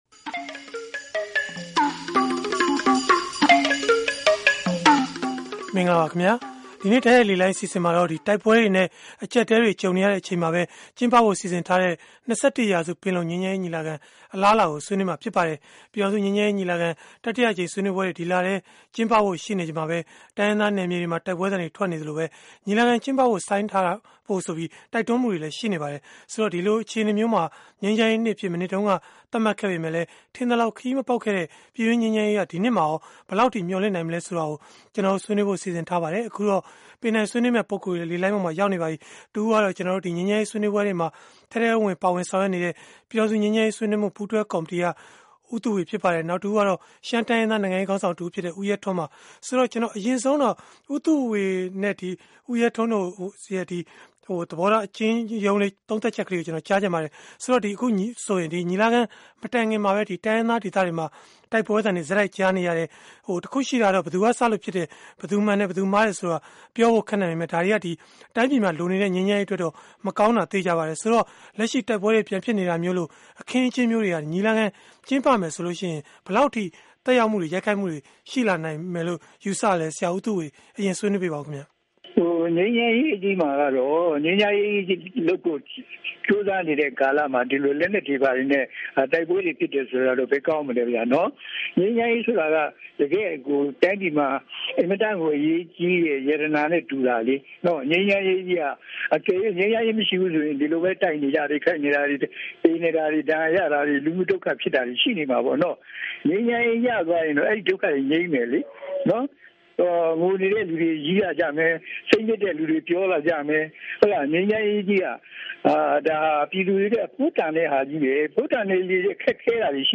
ဗွီအိုအေရဲ့ အင်္ဂါနေ့ည တိုက်ရိုက်လေလှိုင်း အစီအစဉ်မှာ